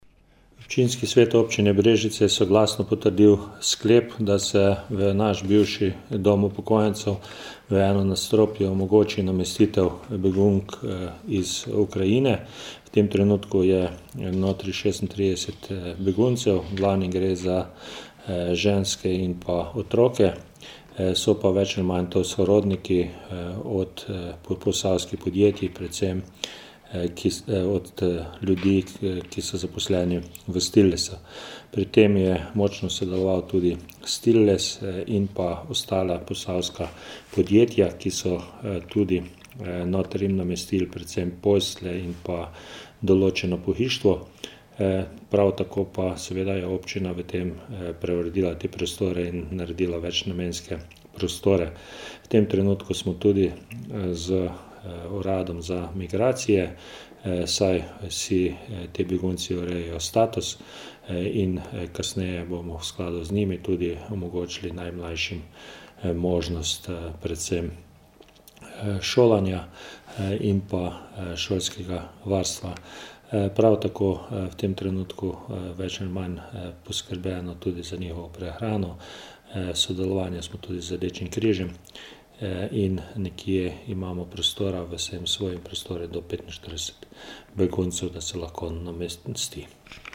izjava_a1_2upan_obaine_brea3_4ice.mp3 (2,2MB)